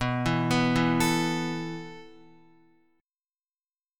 B7sus4 Chord